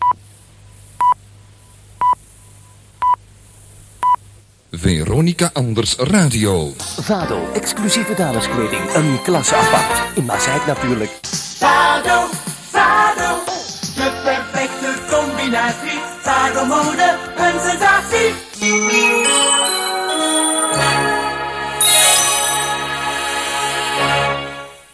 Tijdsein en nieuwsbulletin.mp3